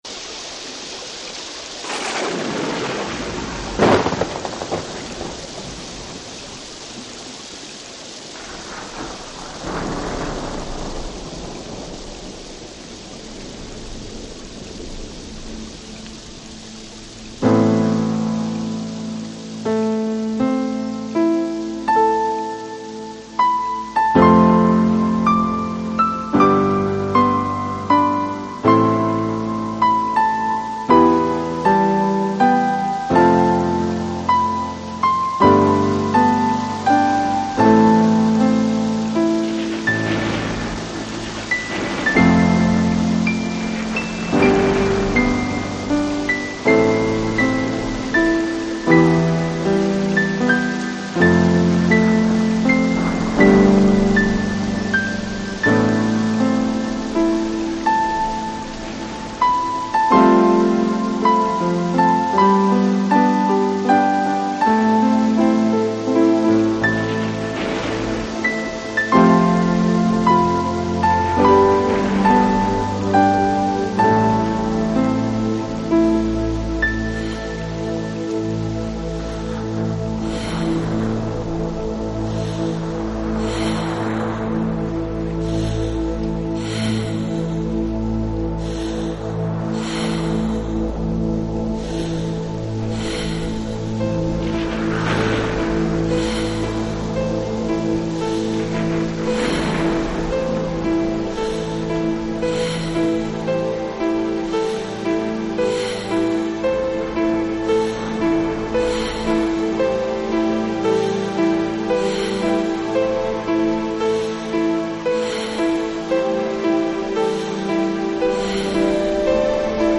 【纯音乐】
音乐类型：New Age